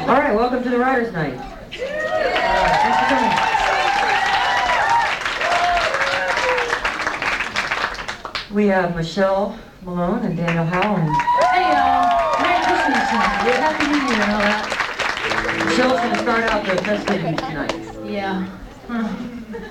(songwriters in the round)
02. introduction (0:19)